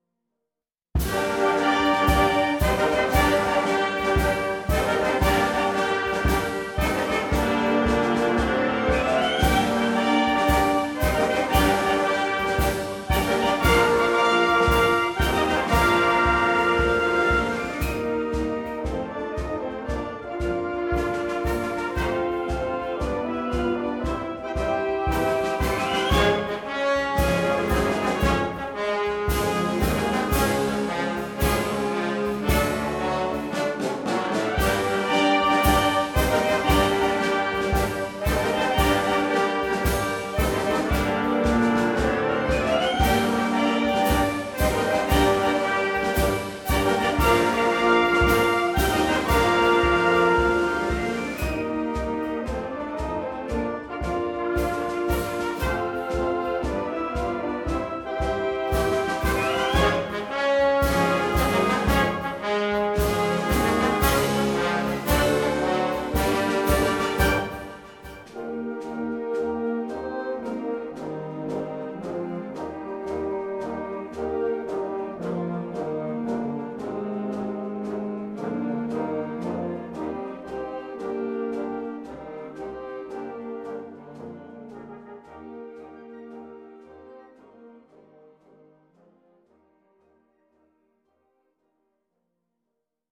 for Wind Band
A classic concert march full of wonderful soaring themes.